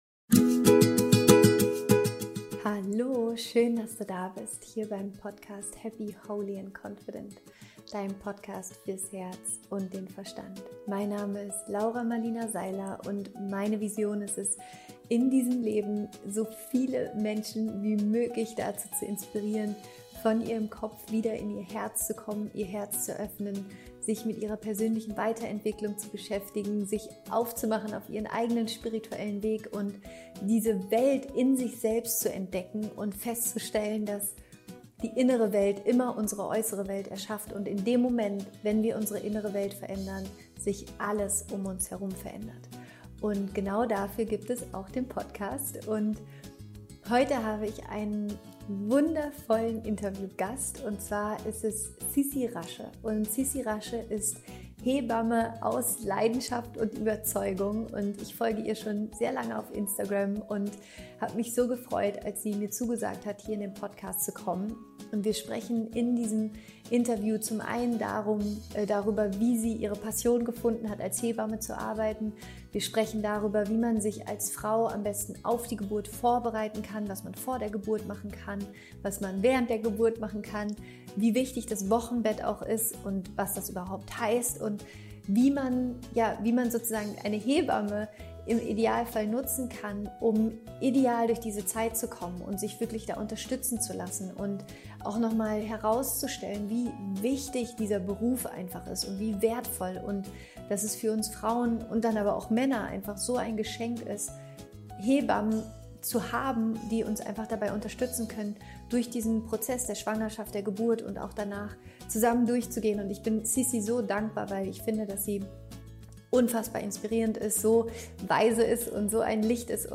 Es ist ein wunderschönes, tiefes und vor allem informatives Gespräch und ich wünsche dir viel Spaß dabei.